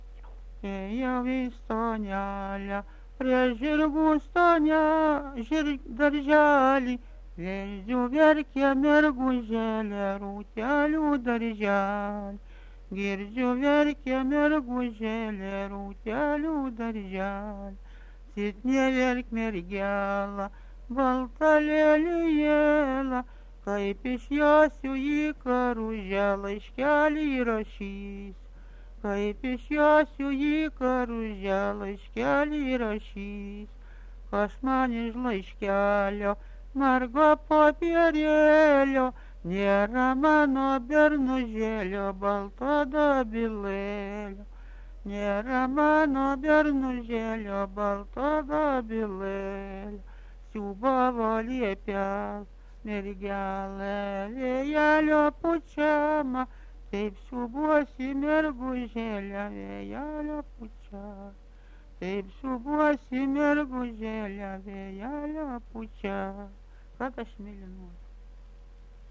daina
Atlikimo pubūdis vokalinis
Pirmų žodžių nesigirdi.